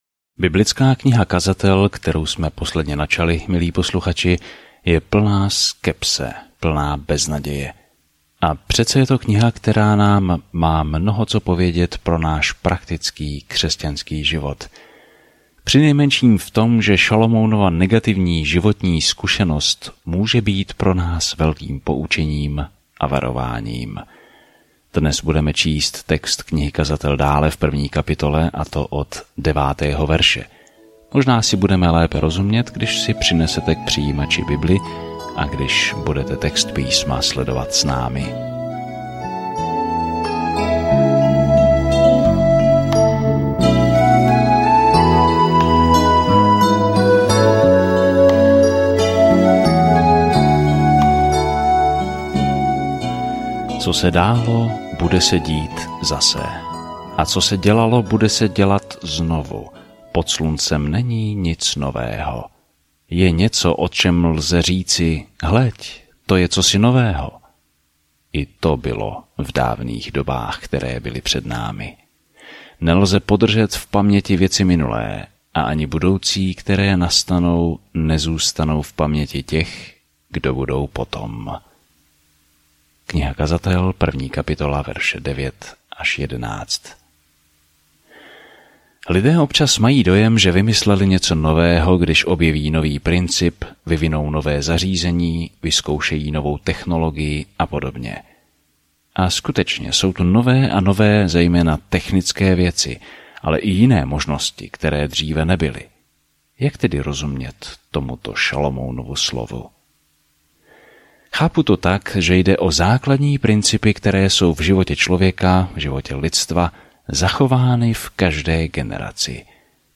Písmo Kazatel 1:9-18 Den 1 Začít tento plán Den 3 O tomto plánu Kazatel je dramatická autobiografie Šalomounova života, když se snažil být šťastný bez Boha. Denně cestujete po Kazateli, posloucháte audiostudii a čtete vybrané verše z Božího slova.